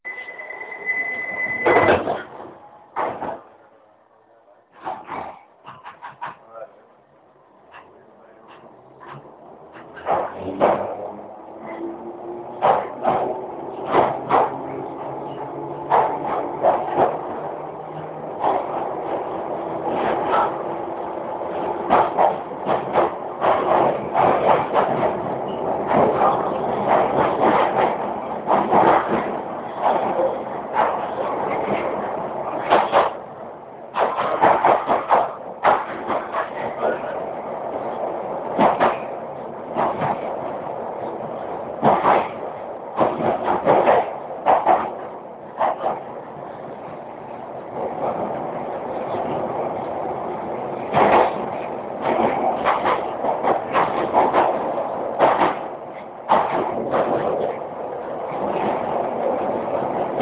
贝克洛3号线车门关闭和离开
描述：这是用我的诺基亚N70录制的一个粗糙的现场录音。
大量的背景噪音。地铁列车上的车门关闭，列车离开。
Tag: 现场记录 伦敦地下 车站 列车